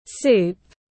Món súp tiếng anh gọi là soup, phiên âm tiếng anh đọc là /suːp/
Soup /suːp/